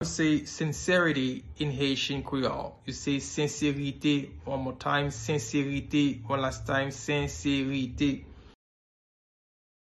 Pronunciation:
27.How-to-say-Sincerity-in-Haitian-Creole-–-Senserite-pronunciation-.mp3